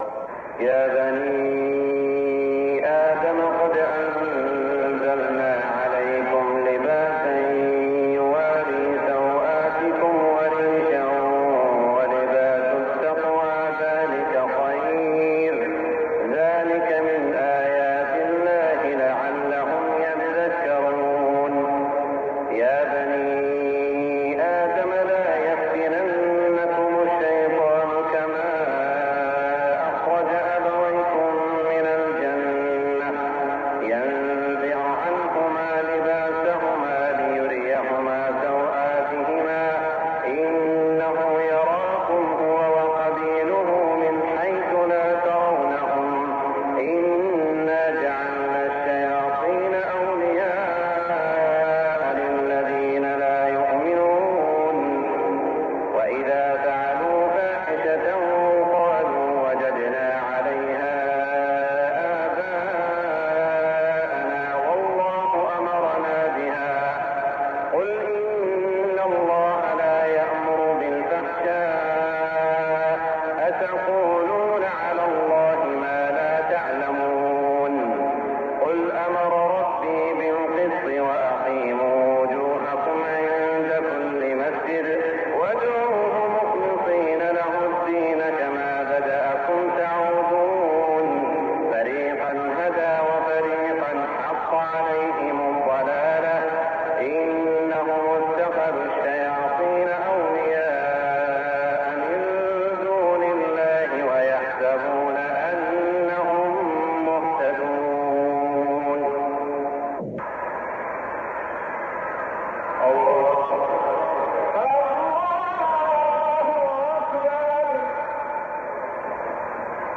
صلاة الفجر 1419هـ من سورة الأعراف > 1419 🕋 > الفروض - تلاوات الحرمين